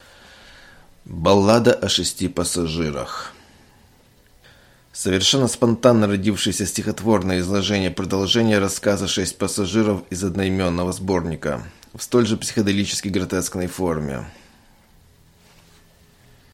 Аудиокнига Баллада о шести пассажирах | Библиотека аудиокниг